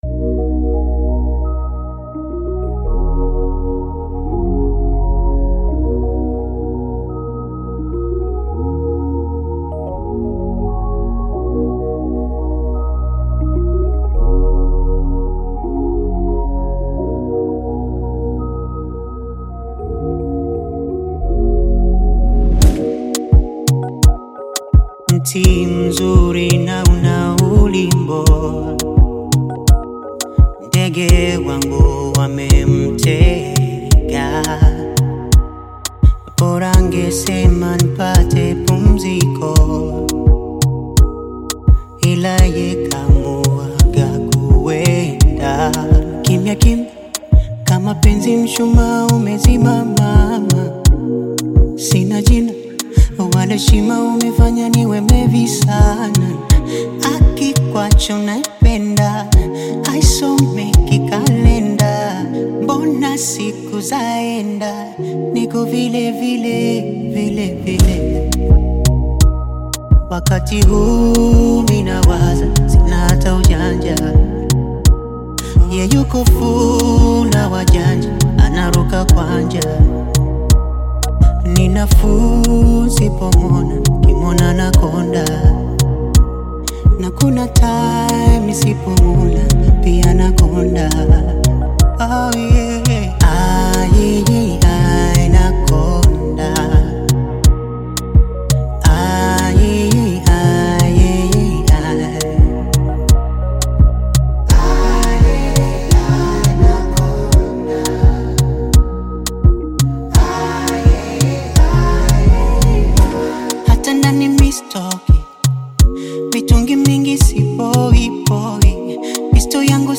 Bongo Flava
Tanzanian Bongo Flava